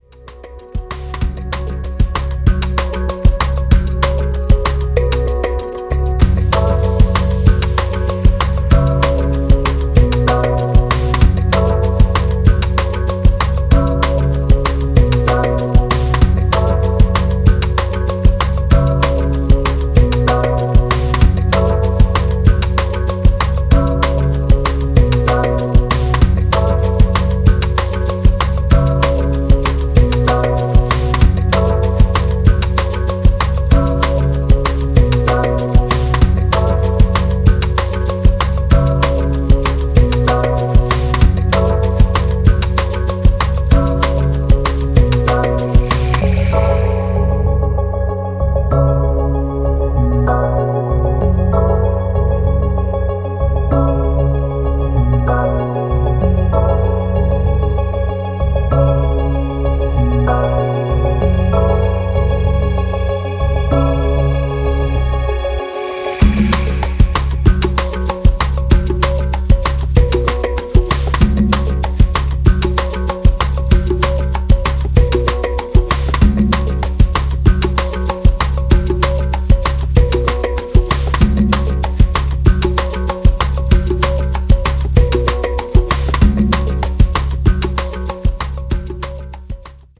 House Breaks